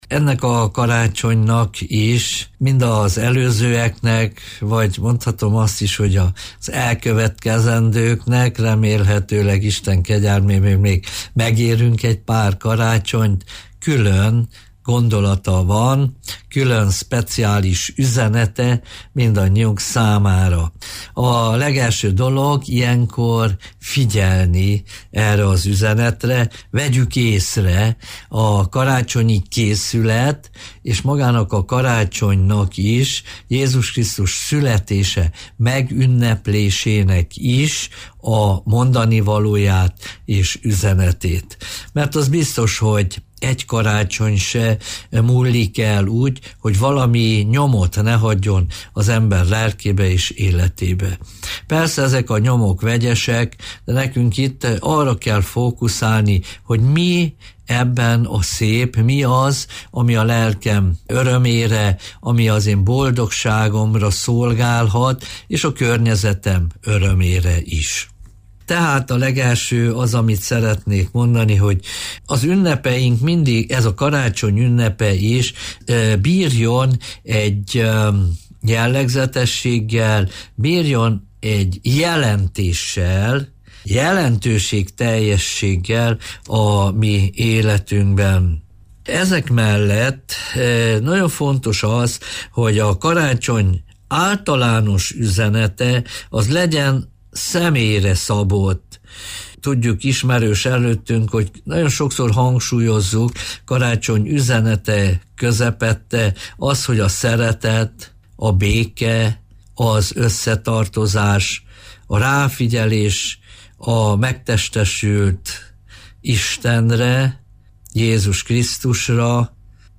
A szeretet ünnepén, arra készülődve, jó érzés meghallgatni egy lelkész gondolatait az ünnepkörrel kapcsolatban.